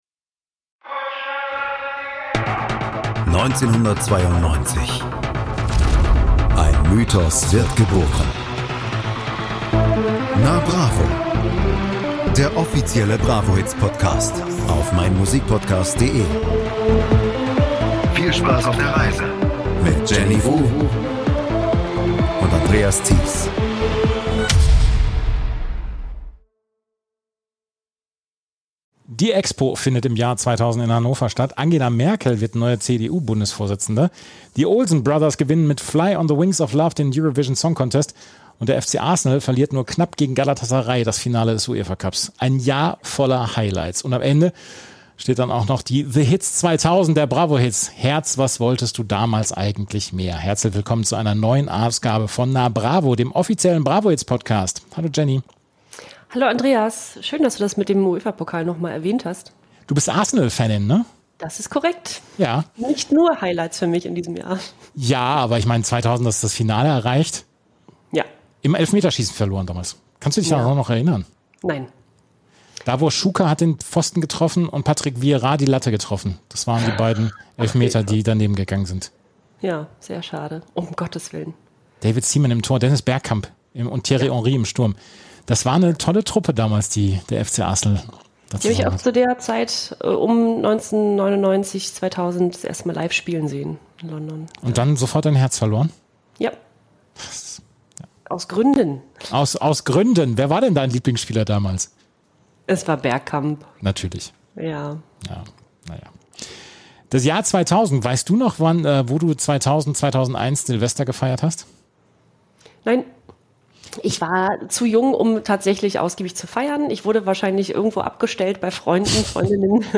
Alle Titel, die wir in den letzten Ausgaben noch nicht besprochen haben, werden angespielt und seziert. Ein kurzer Blick wird auch auf die BRAVO Ottos geworfen, die in jedem Jahr vom Jugendmagazin BRAVO verliehen werden.